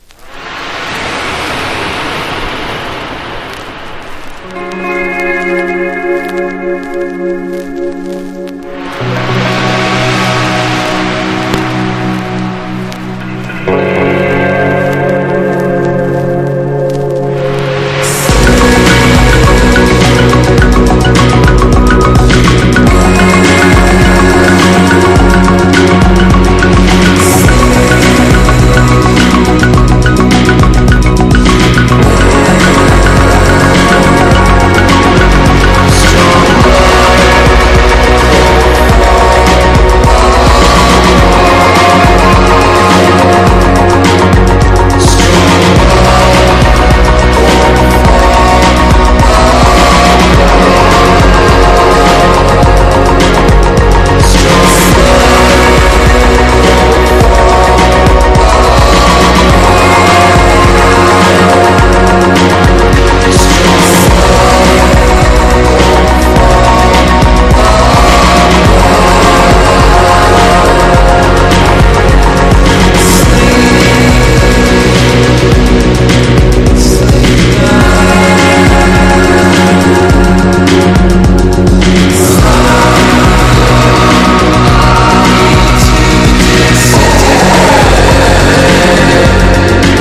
PROGRESSIVE# 10’s ROCK# GARAGE ROCK (90-20’s)